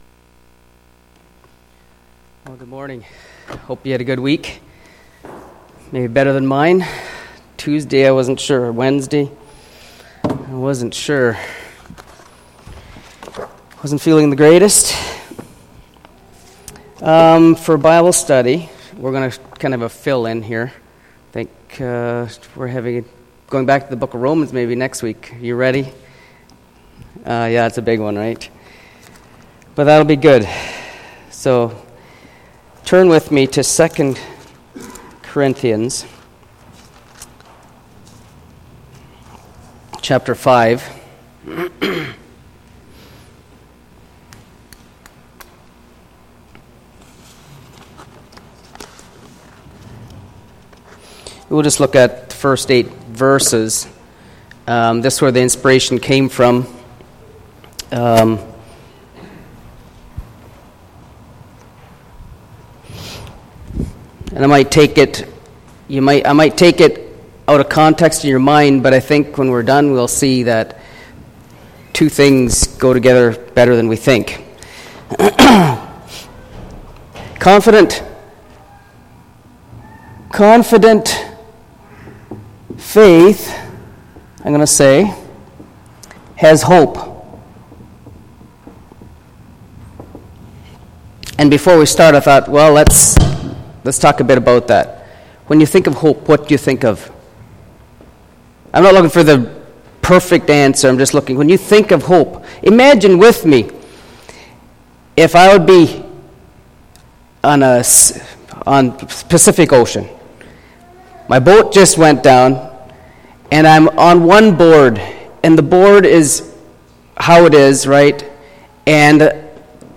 0035 Bible Study.mp3